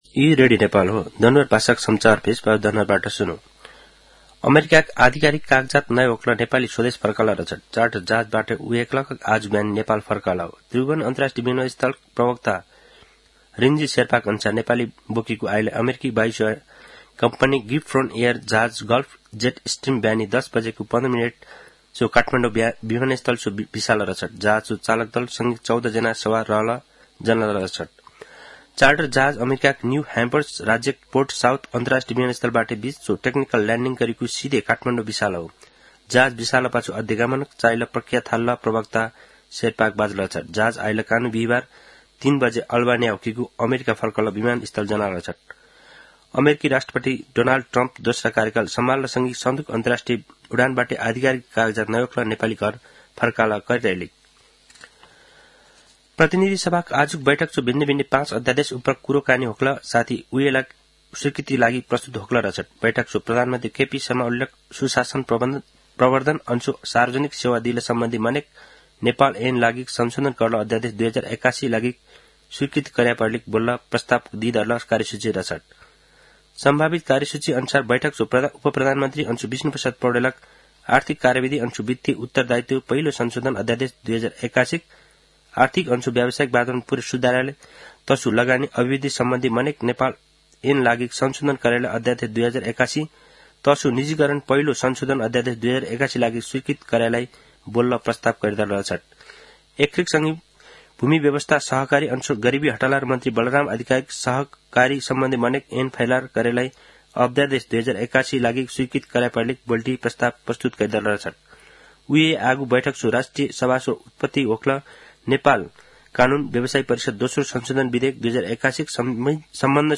दनुवार भाषामा समाचार : २२ फागुन , २०८१
danuwar-news-1-4.mp3